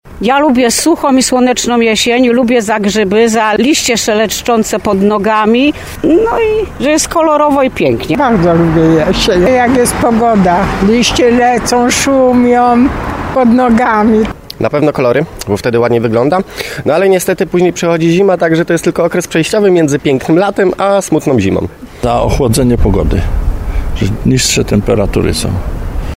Dziś rozpoczyna się astronomiczna jesień. Pytamy tarnowian, za co lubią tę porę roku